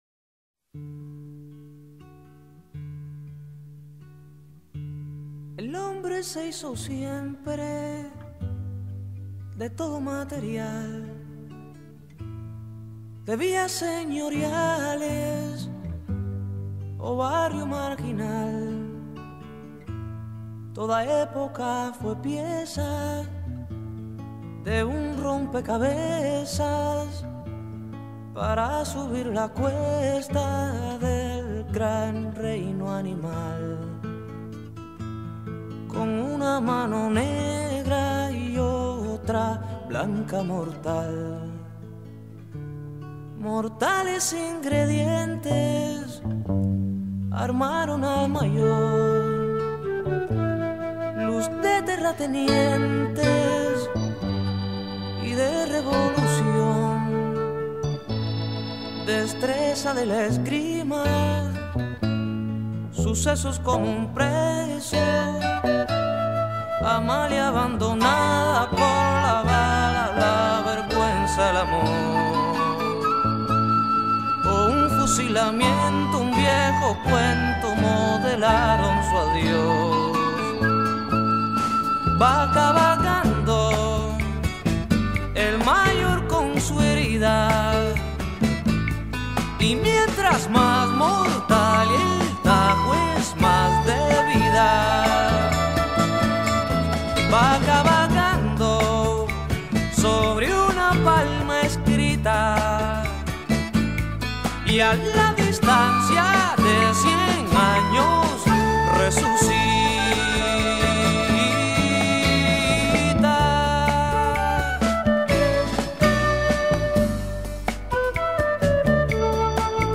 Canción